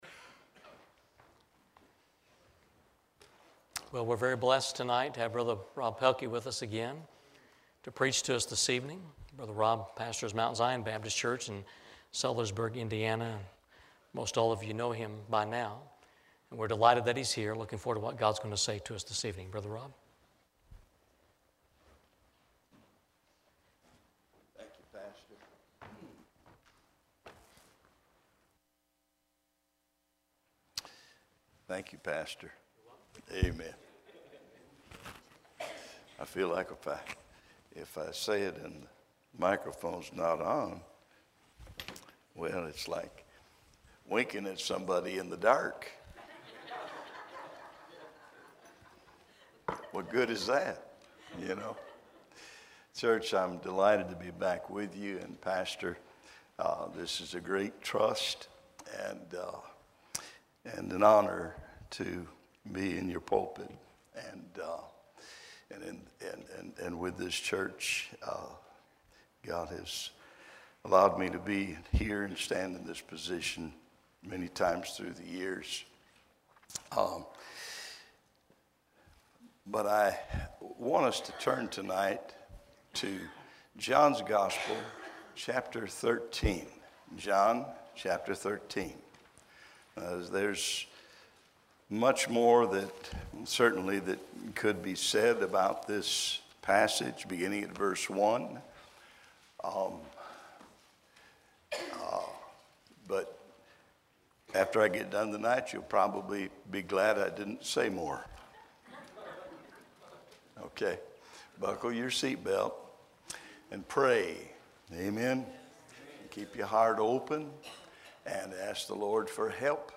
Eastern Gate Baptist Church - Meekness and Majesty